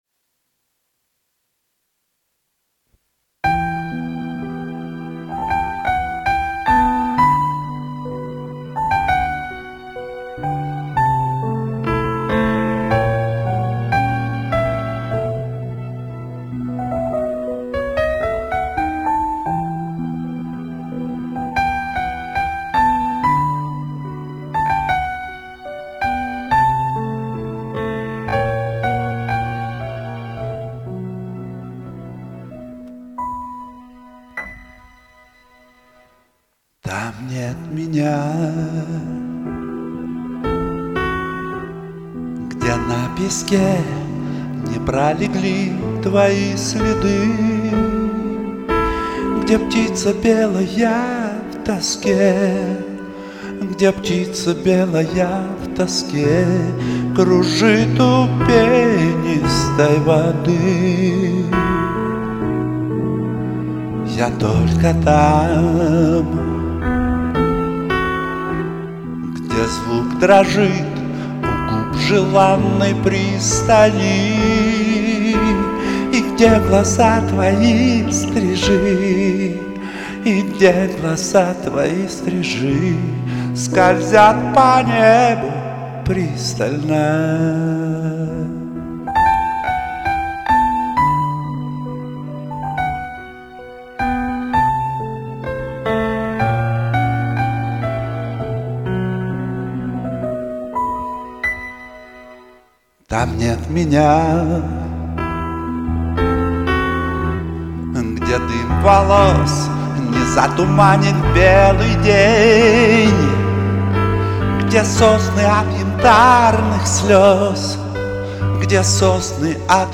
эмоции зашкаливают.